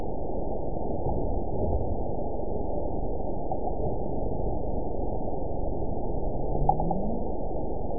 event 912531 date 03/28/22 time 21:09:48 GMT (3 years, 1 month ago) score 9.49 location TSS-AB05 detected by nrw target species NRW annotations +NRW Spectrogram: Frequency (kHz) vs. Time (s) audio not available .wav